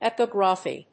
音節e・pig・ra・phy 発音記号・読み方
/ɪpígrəfi(米国英語)/
epigraphy.mp3